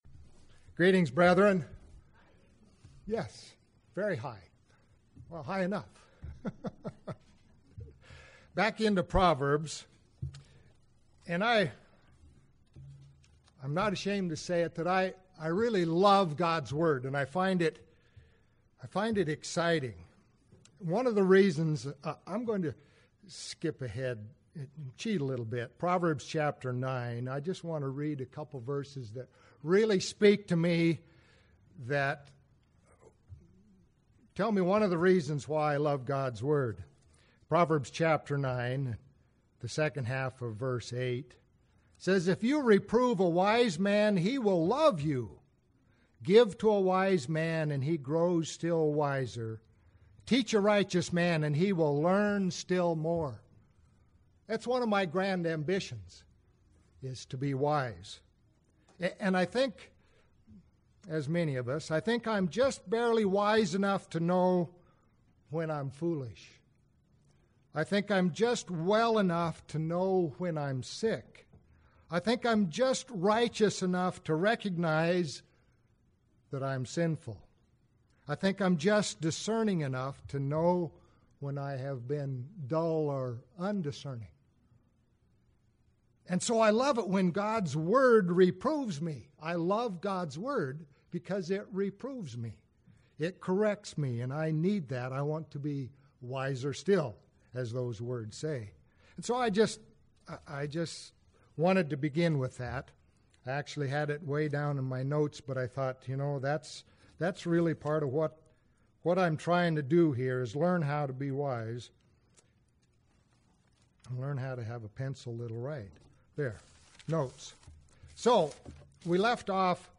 Given in Medford, OR